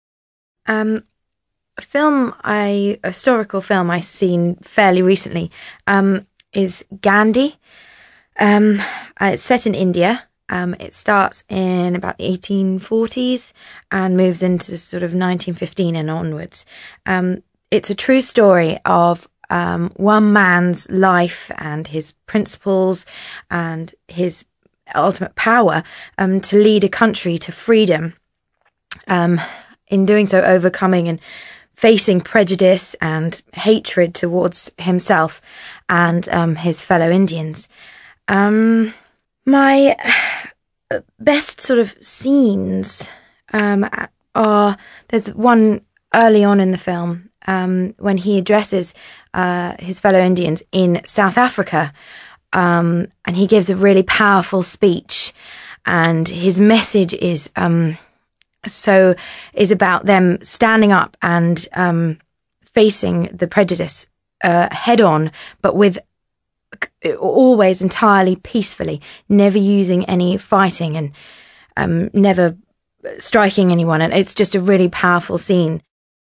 ACTIVITY 58: You are going to listen to a first woman taking about a historical film she has seen.